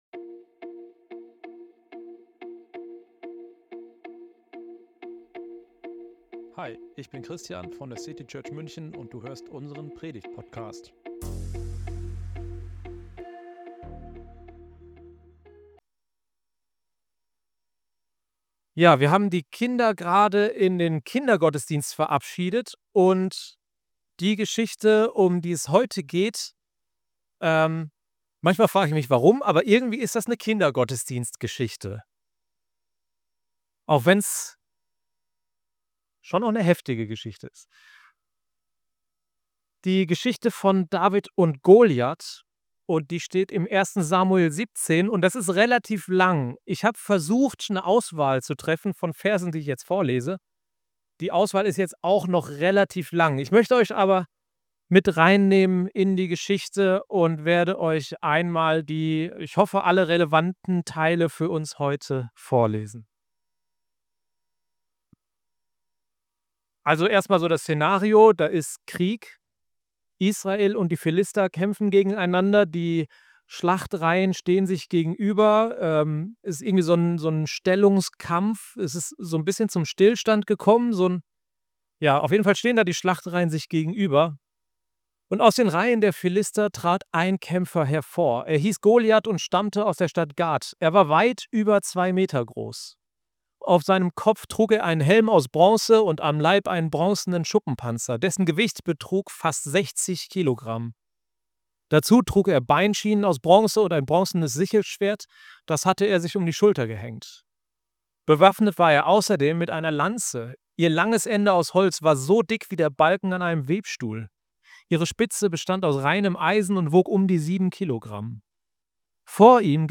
Eine Predigt über deine „Schleuder“ und Gottes Vertrauen in dich.